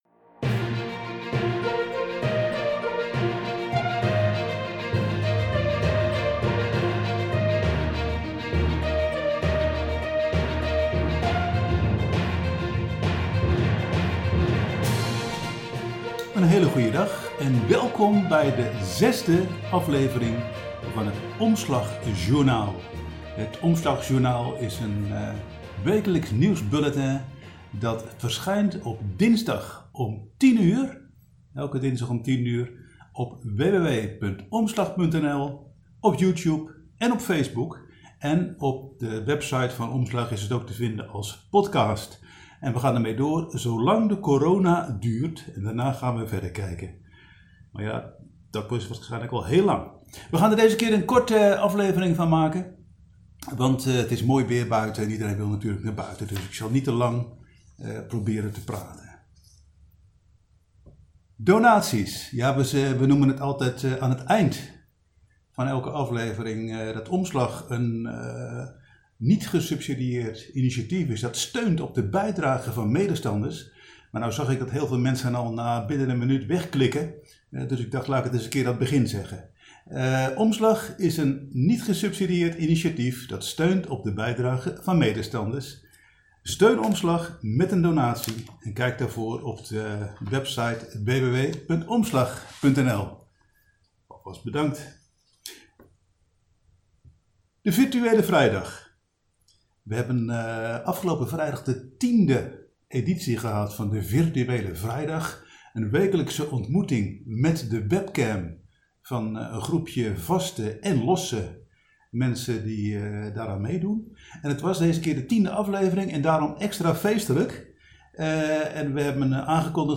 Omslag Nieuws als podcast (Alleen geluid) Klik hier